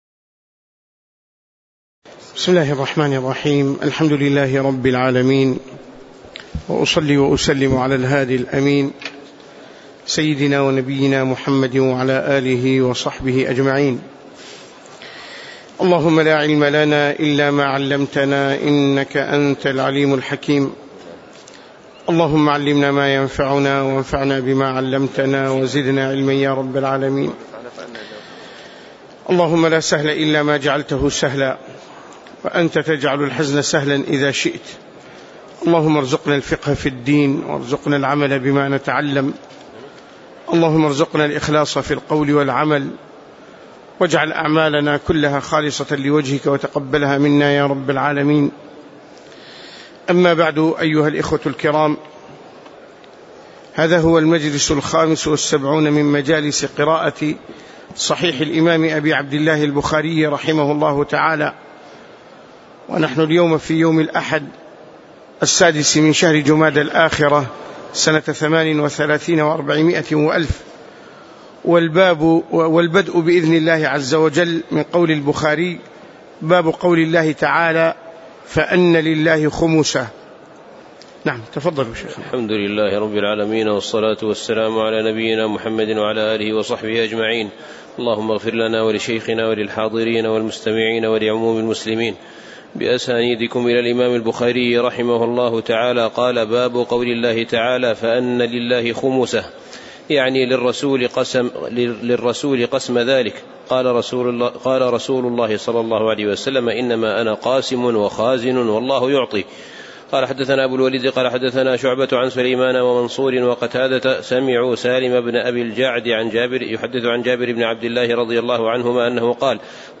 تاريخ النشر ٦ جمادى الآخرة ١٤٣٨ هـ المكان: المسجد النبوي الشيخ